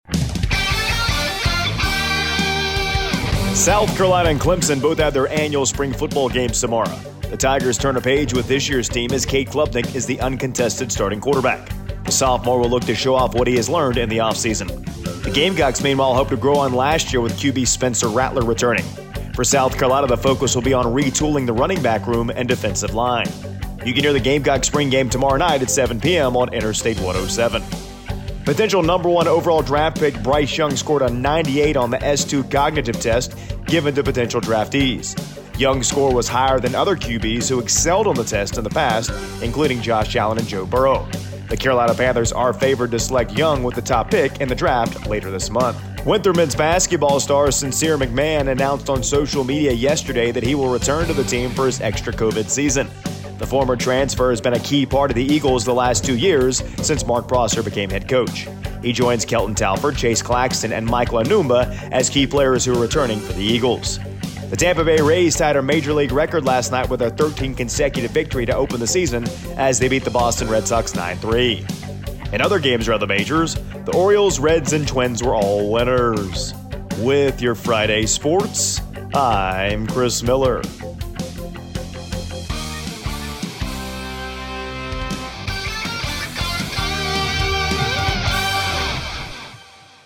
AUDIO: Thursday Morning Sports Report